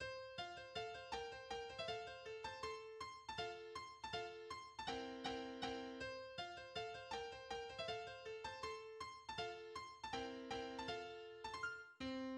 Genre Sonate pour piano et violon
Rondeau: Allegretto grazioso, en fa majeur, à  , 1 section répétée 2 fois (mesures 85 à 92), 203 mesures - partition
Introduction au piano du Rondeau: Allegretto grazioso: